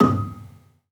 Gambang-D#5-f.wav